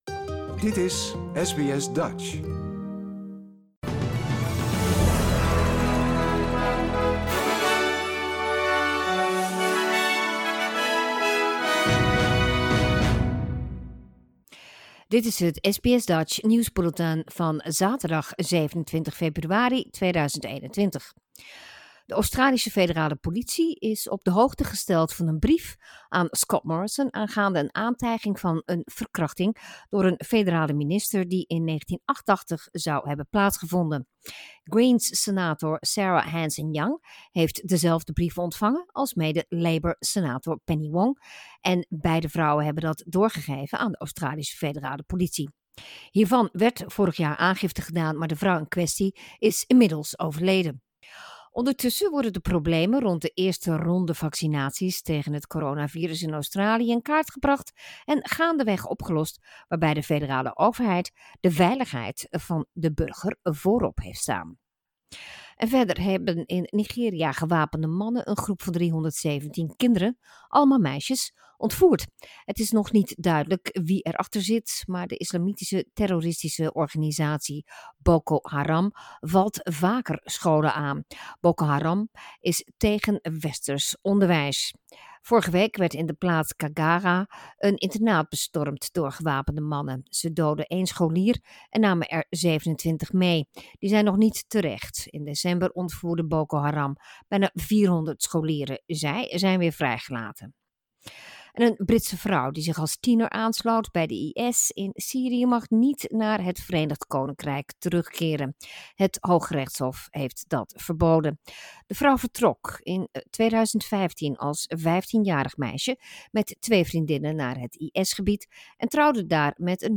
Nederlands/Australisch SBS Dutch nieuwsbulletin zaterdag 27 februari 2021